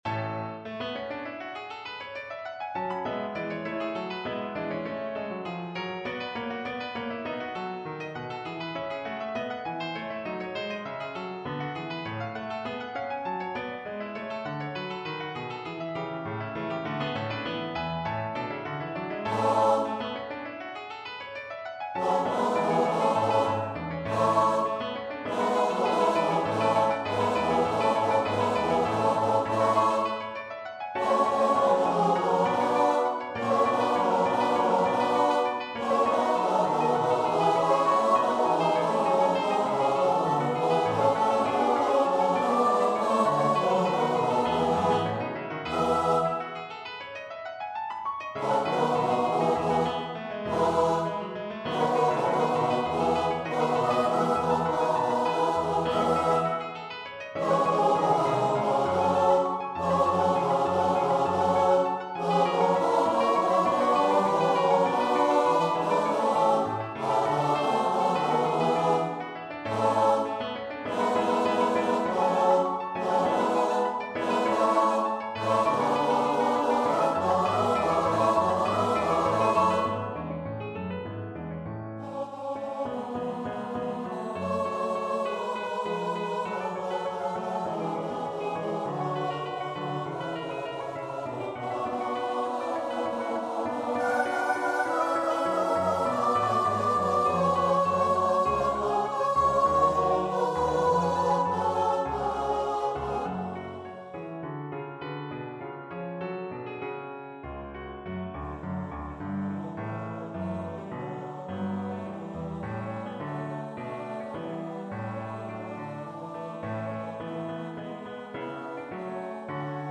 Free Sheet music for Choir
Classical (View more Classical Choir Music)